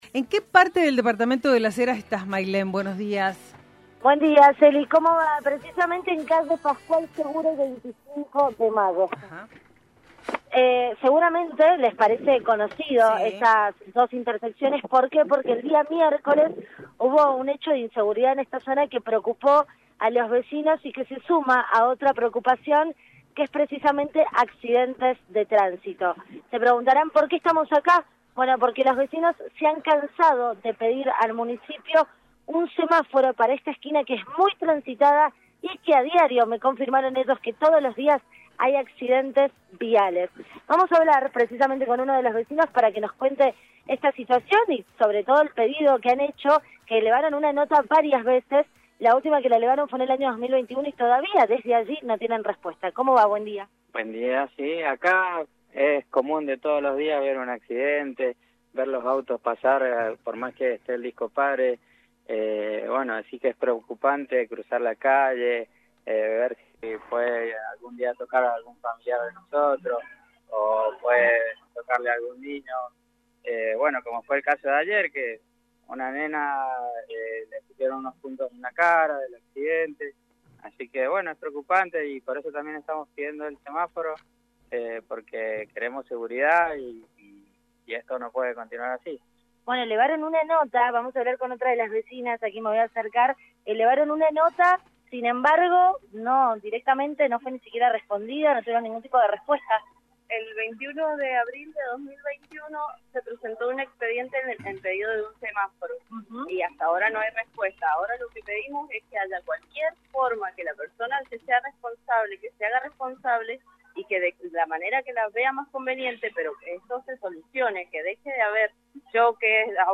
LVDiez - Radio de Cuyo - Móvil de LVDiez desde Las Heras